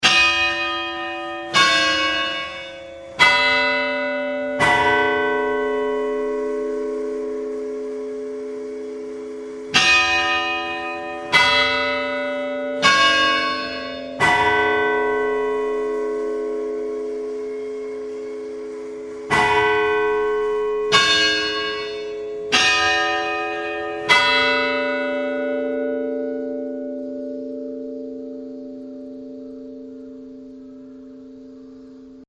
10.大隈記念講堂鐘楼
大小4つの鐘で奏でるハーモニーは、ロンドン・ウエストミンスター寺院と同じ方式です。鐘の鳴る時間8・9・12・16・20・21時の1日6回。